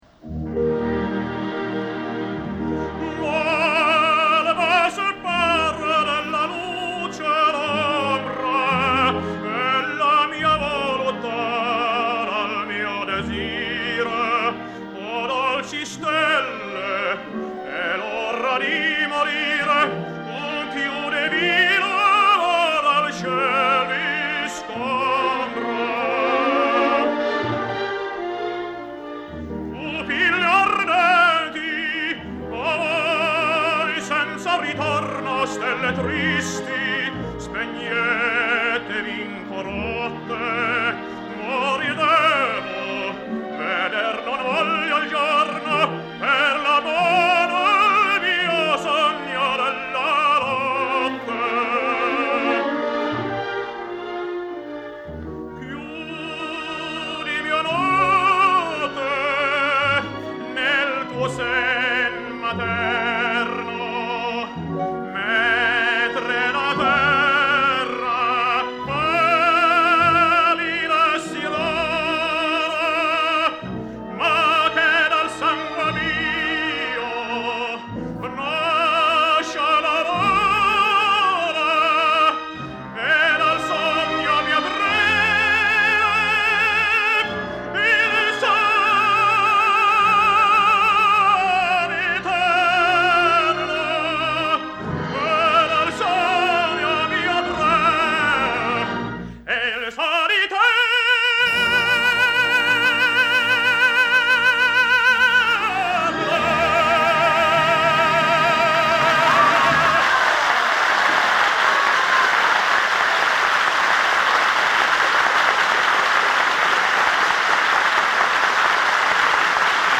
2. Jussi’s first studio recording, set down in Stockholm on 11 August 1949, conducted by Nils Grevillius.
2. Jussi's first studio recording 1949